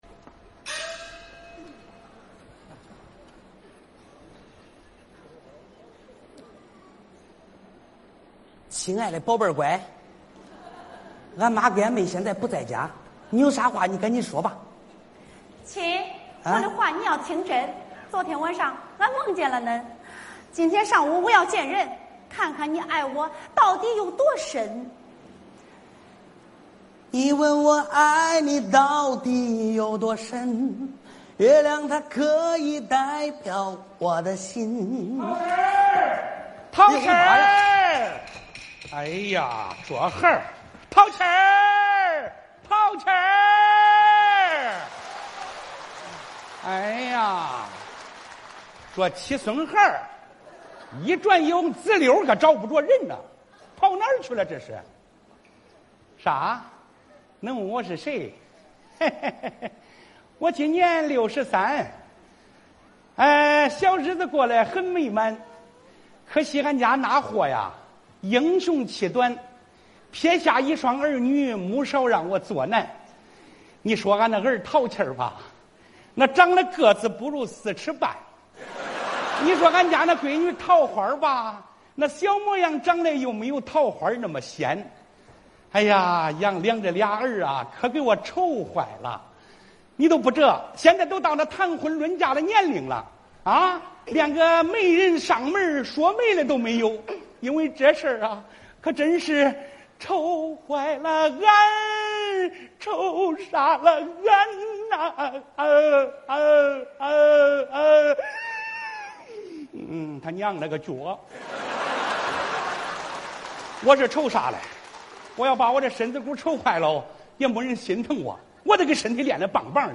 曹随风 范军 小品《新柜中缘》.mp3_东方“织”乐